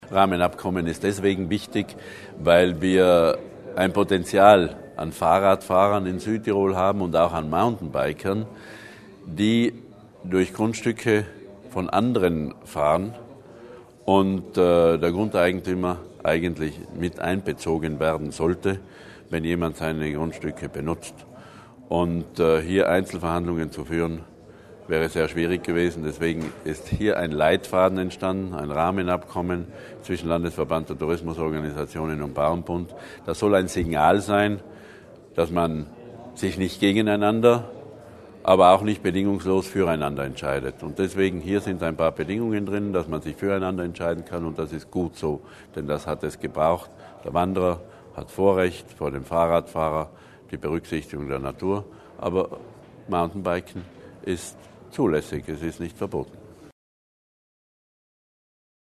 Landesrat Berger zur Bedeutung des Abkommens